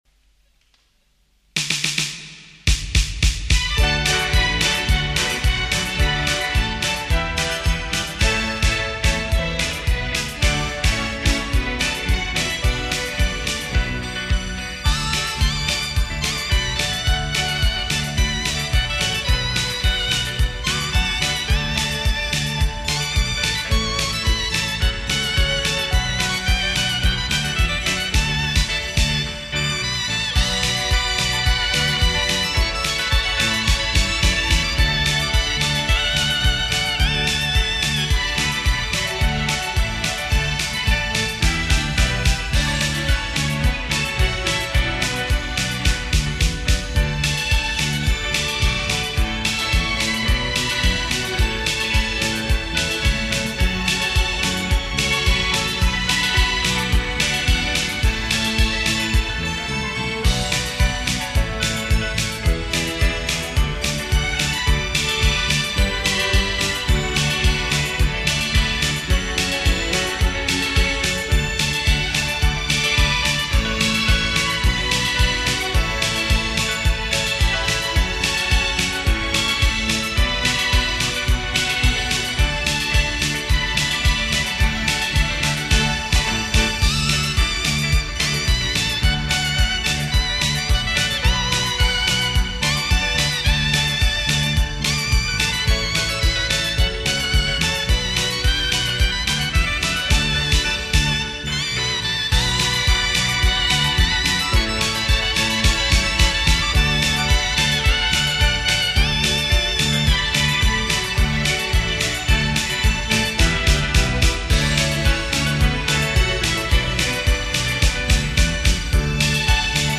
快四